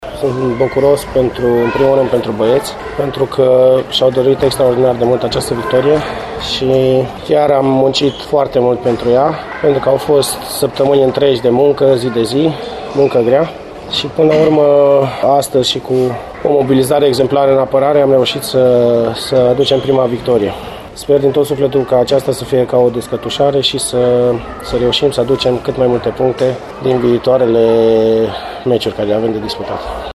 La final de întâlnire, antrenorul